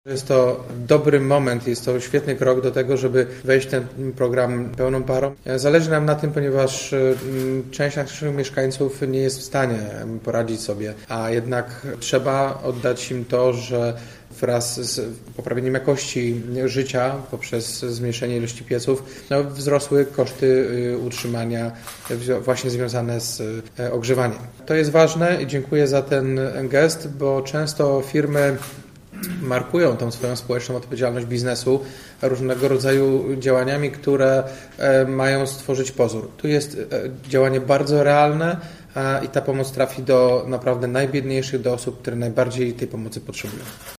Po zrealizowaniu bardzo ważnego dla całego miasta programu Kawka,czyli likwidacji pieców w kamienicach w centrum miasta okazało się, że część mieszkańców ma problem z regulowaniem rachunków za ciepło – przyznaje prezydent Jacek Wójcicki: